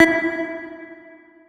key-press-4.wav